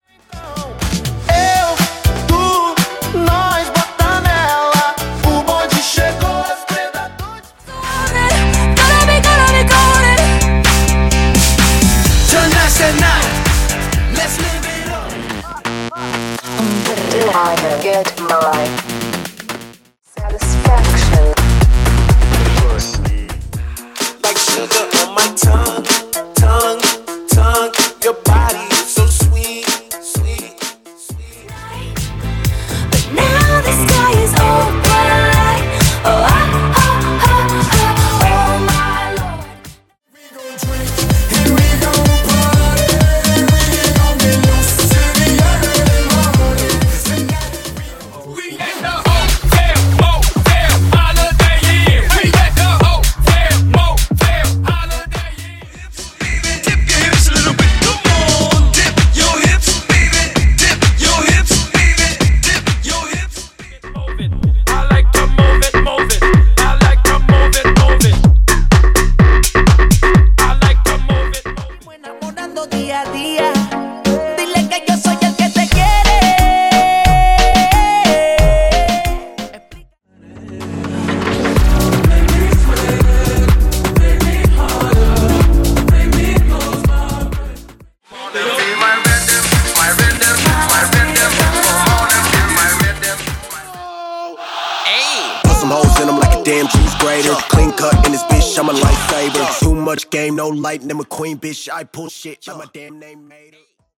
DANCE , EDM , RE-DRUM